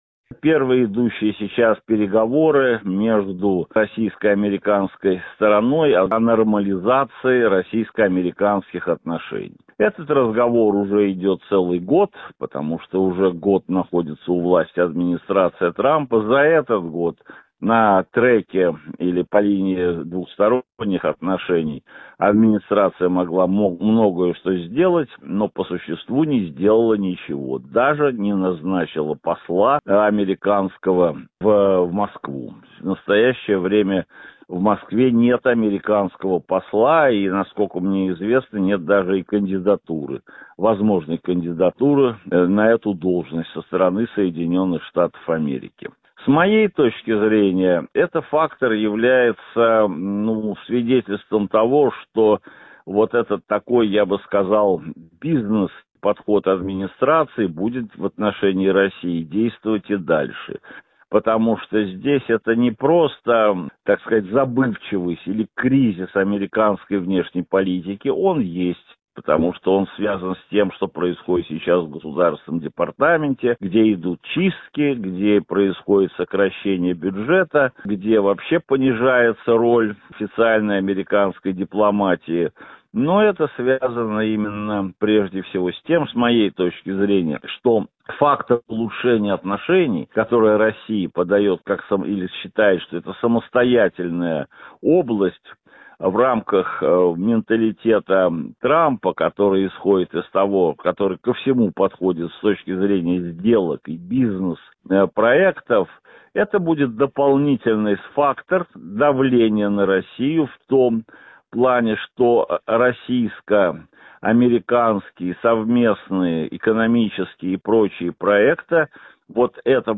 аудиоверсия программы